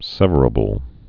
(sĕvər-ə-bəl, sĕvrə-)